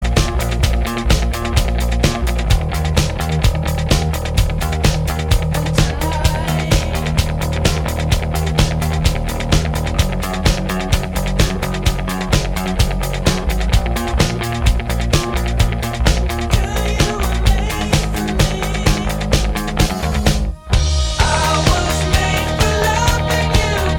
Minus Guitars Rock 4:38 Buy £1.50